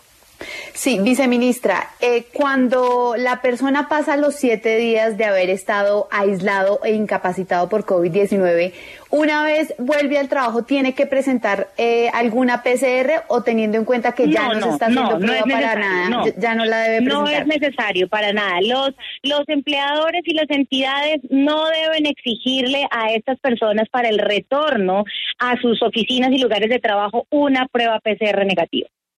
En diálogo con la W, Isis Andrea Muñoz, viceministra de Relaciones Laborales e Inspección del Ministerio del Trabajo explicó.